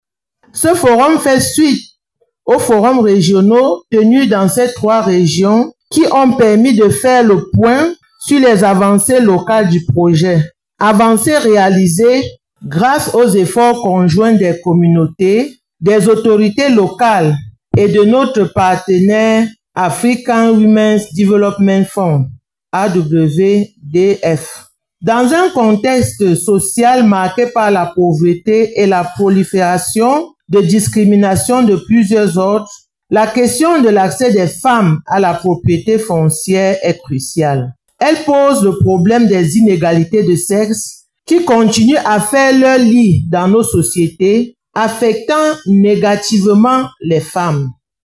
Le Gouverneur de la région du Centre s’est exprimé au cours du forum national sur les femmes et le foncier qui s’est tenu le jeudi 07 novembre 2024 à Yaoundé, dans la salle des réunions du conseil régional pour le Centre.
Extrait du discours